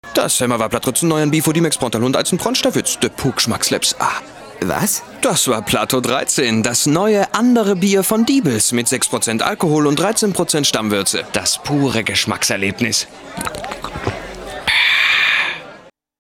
deutscher Sprecher
Sprechprobe: Industrie (Muttersprache):
german male voice over